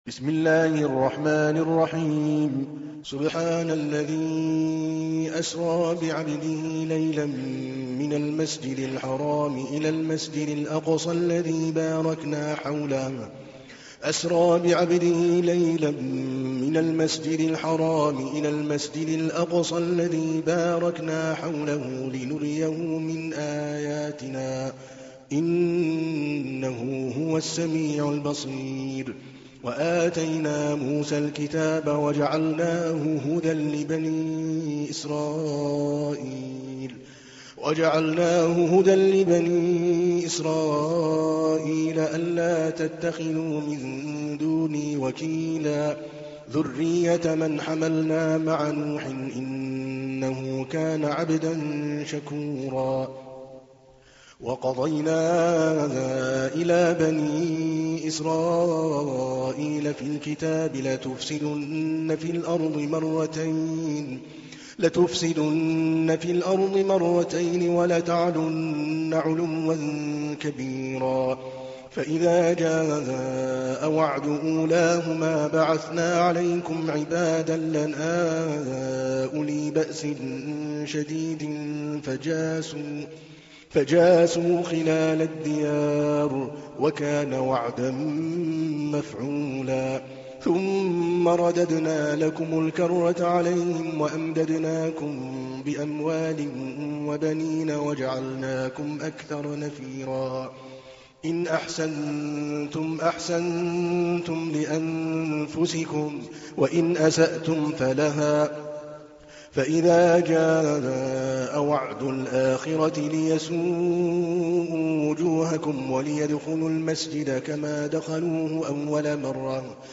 تحميل : 17. سورة الإسراء / القارئ عادل الكلباني / القرآن الكريم / موقع يا حسين